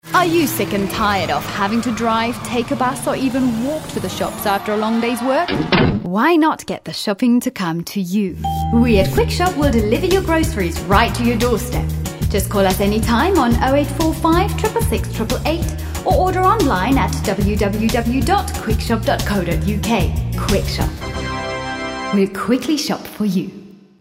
Junge, freundliche, warme, fröhliche englische Stimme für Voice Overs, Synchronisation und Werbung.
Sprechprobe: Werbung (Muttersprache):
A warm, friendly, young and happy sounding voice.